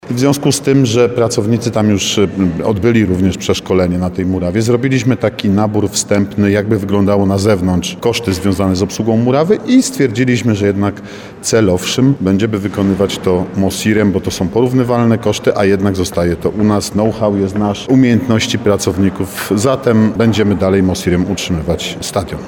Jak mówi wiceprezydent Nowego Sącza Artur Bochenek, MOSiR ma już doświadczenie z murawą na boisku w Zawadzie.